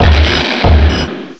cry_not_conkeldurr.aif